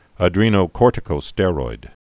(ə-drēnō-kôrtĭ-kō-stĕroid, -stîr-)